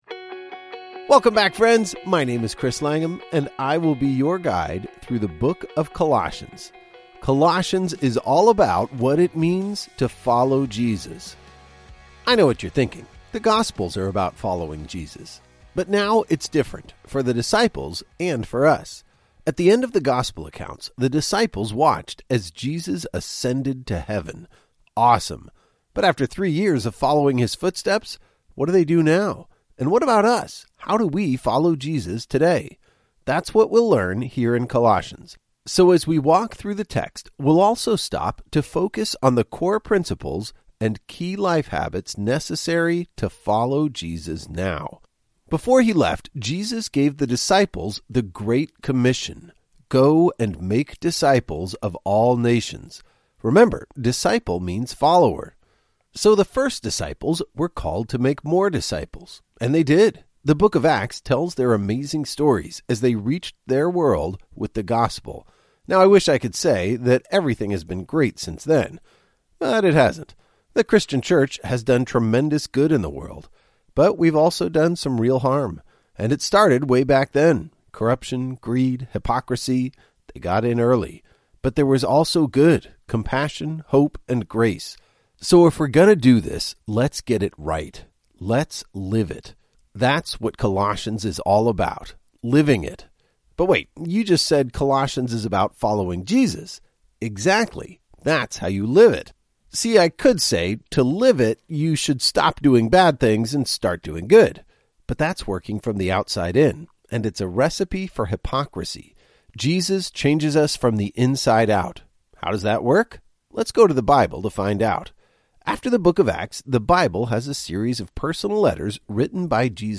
Welcome to Colossians Explained, a chapter-by-chapter audio guide by Through the Word.